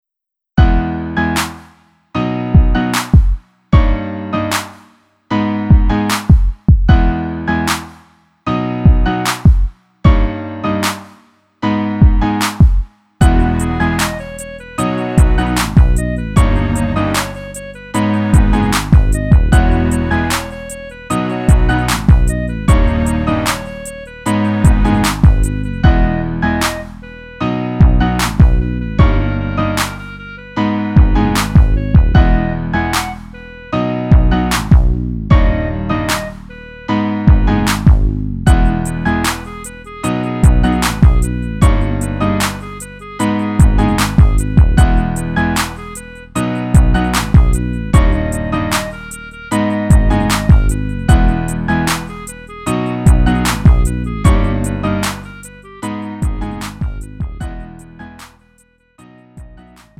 음정 원키 4:02
장르 구분 Lite MR
Lite MR은 저렴한 가격에 간단한 연습이나 취미용으로 활용할 수 있는 가벼운 반주입니다.